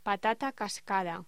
Locución: Patata cascada
voz